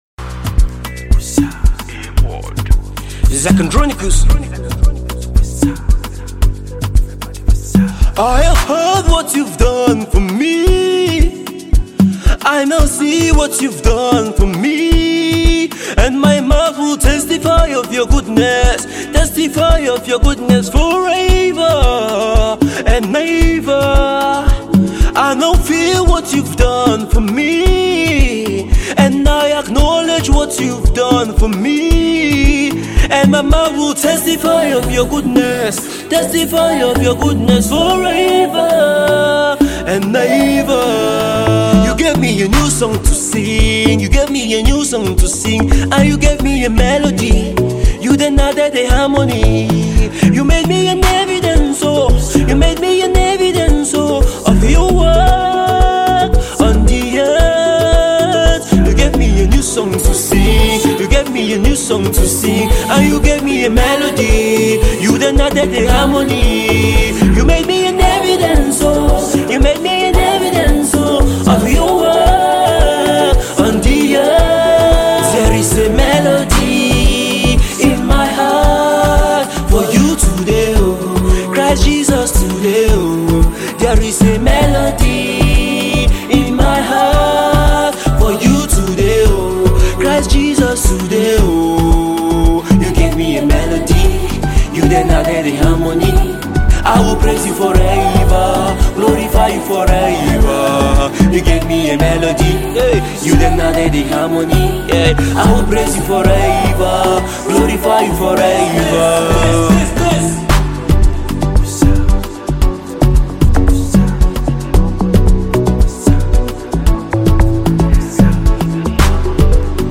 gospel
soul-stirring track